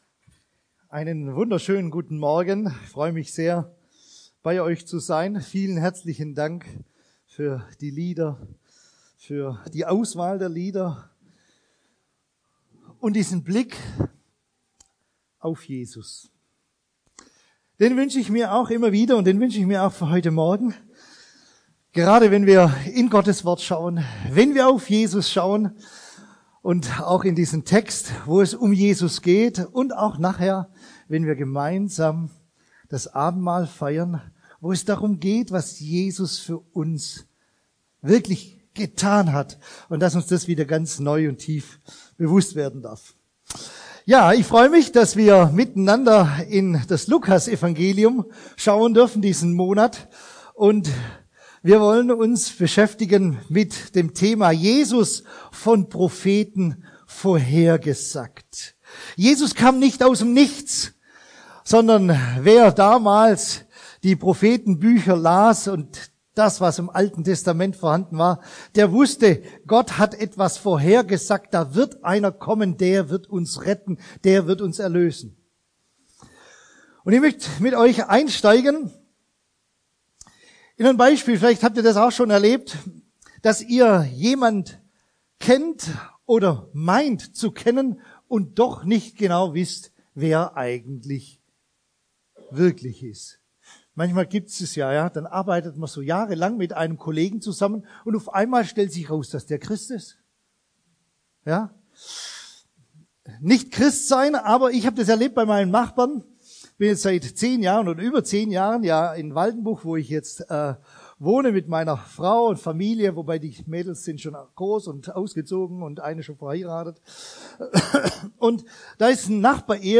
Predigten aus der Fuggi